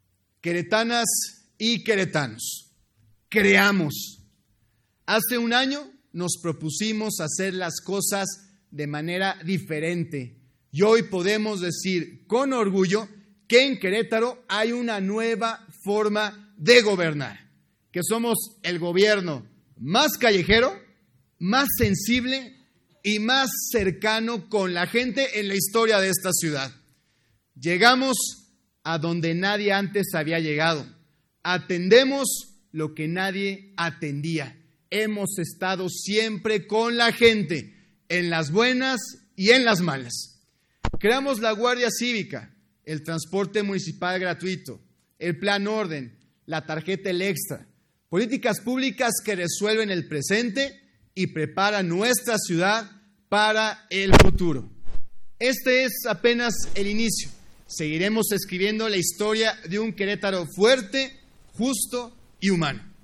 El presidetne municipal de Querétaro, Felipe Fernando “Felifer” Macías Trejo, rindió su Primer Informe de Gobierno ante el Ayuntamiento de Querétaro, donde destacó acciones en materia de seguridad y desarrollo social.
En Sesión Solemne de cabildo, “Felifer” Macías compartió los avances de su primer año de administración, donde llamó a la población a sumarse a su proyecto y crear un futuro mejor.